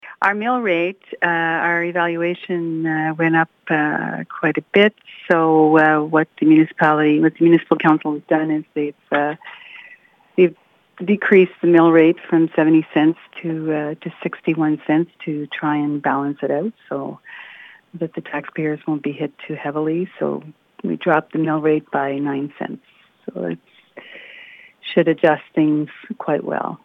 Litchfield Mayor Colleen Larivière spoke with CHIP 101.9 about the municipality’s budget. She started off by pointing out that they had reduced the mill rate by 9 cents in response to increases in assessment values.
Clip-Colleen-Lariviere-mill-rate.mp3